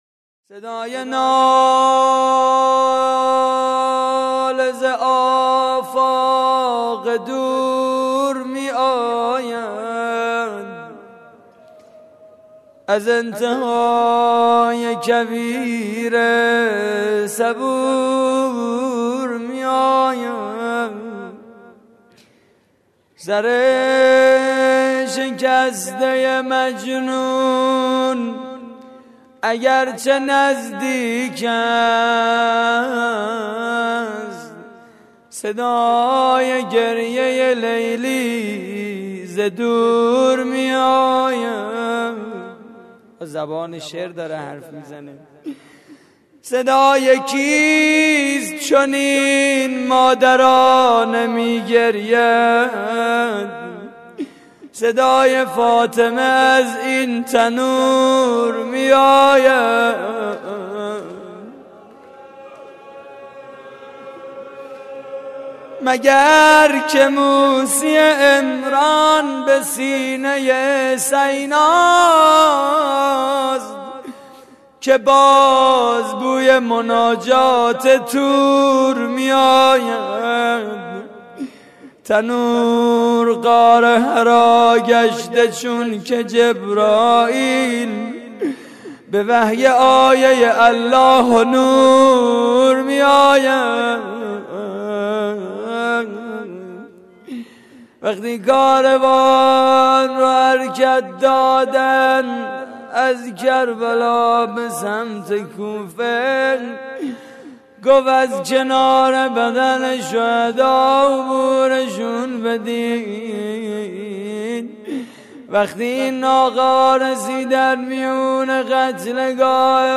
روضه پایانی: صدای ناله ز آفاق دور می‌آید
مراسم عزاداری شب دوازدهم (محرم 1433) / هیئت الزهرا (س) – دانشگاه صنعتی شریف؛